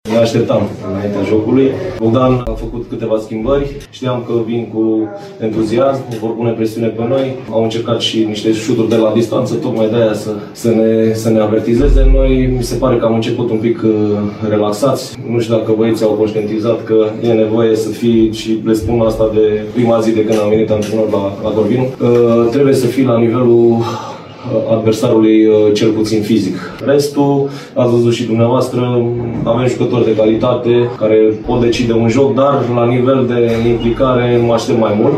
În ciuda victoriei, antrenorul Corvinului, Florin Maxim, are motive de nemulțumire – mai ales pentru prima repriză: